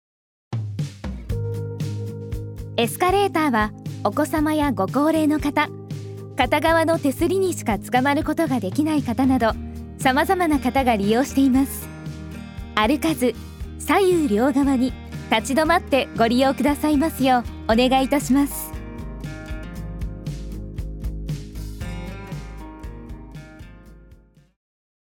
エスカレーター設置施設向け音声案内
エスカレーター設置施設向けの音声案内データです。